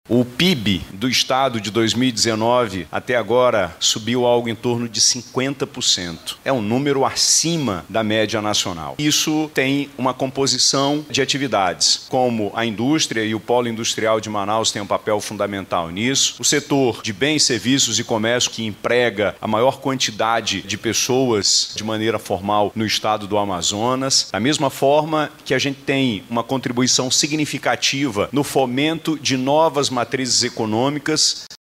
Durante o encontro, o governador do Amazonas, Wilson Lima, destacou o crescimento do PIB do Estado e a contribuição do Setor Industrial.